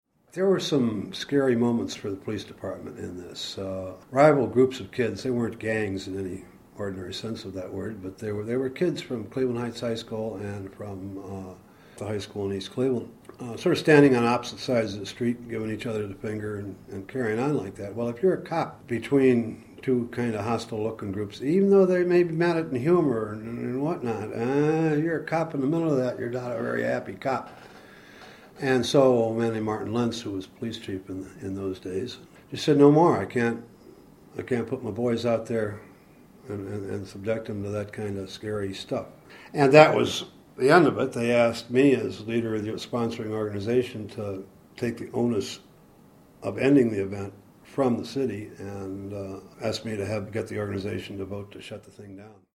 | Source: Cleveland Regional Oral History Collection